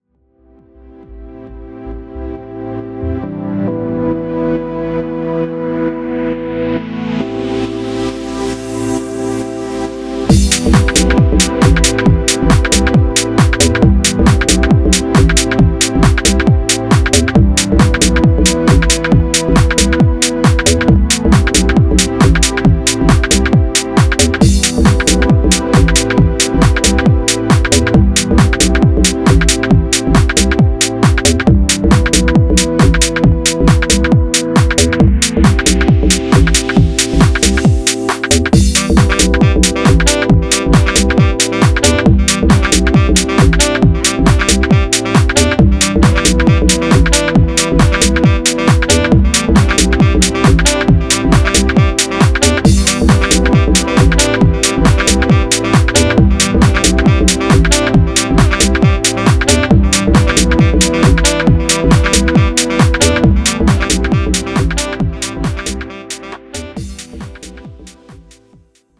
Beautiful chilled garage vibes, sax and strings